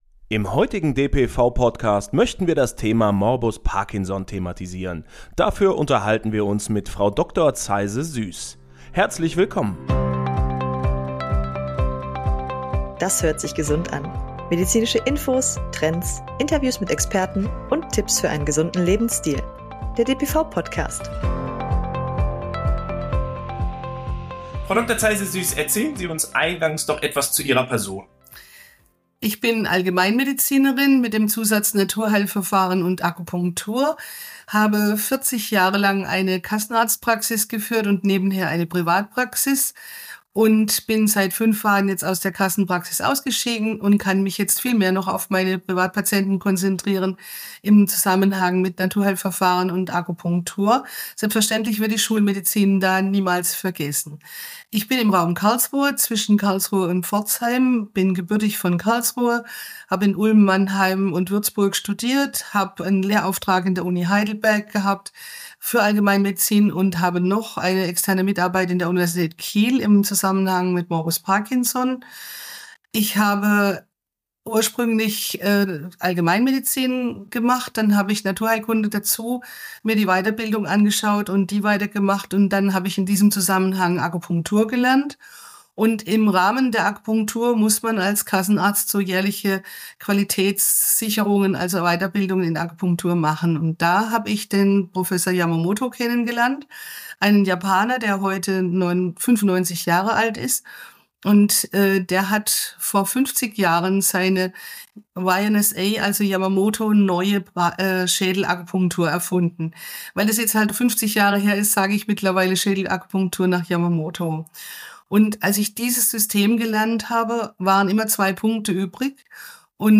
Entdecken Sie in diesem inspirierenden dpv-Interview die transformative Wirkung der multimodalen Behandlung auf Morbus Parkinson.